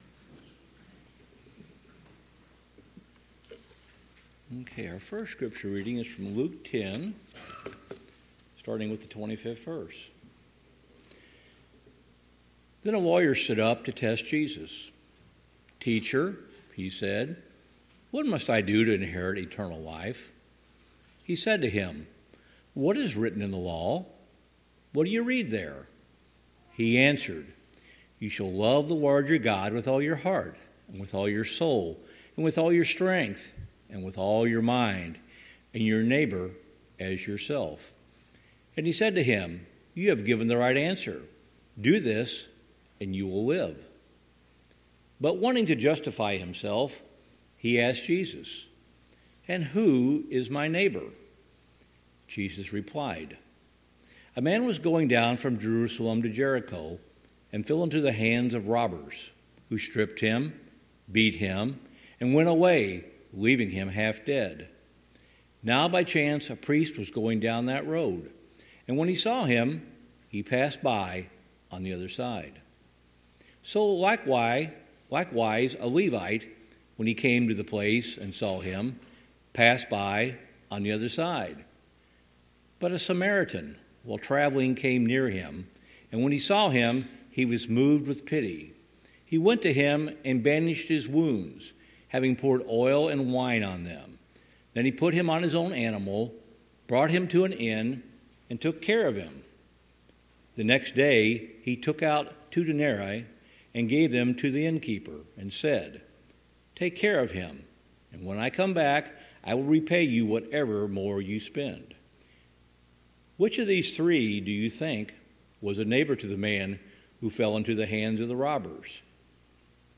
Passage: Luke 10:25-37; Matthew 18:21-35 Service Type: Sunday Morning